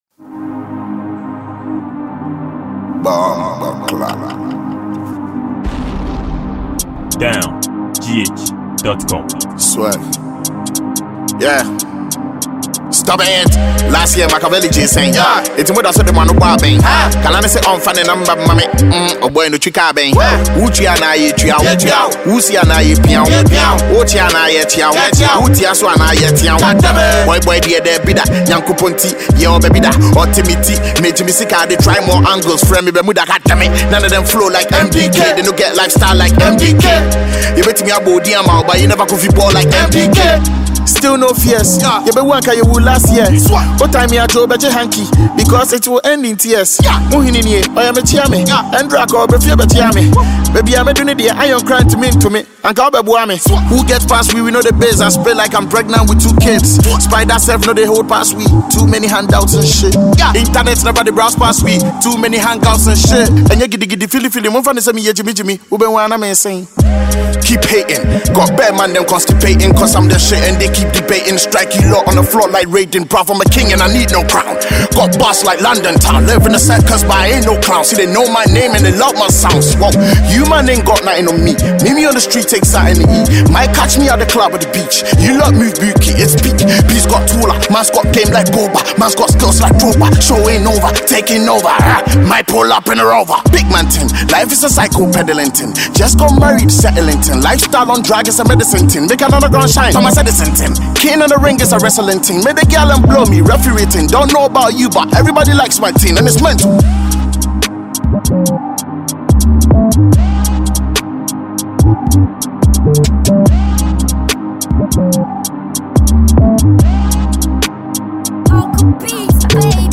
a free Mp3 Afrobeat-Hiphop song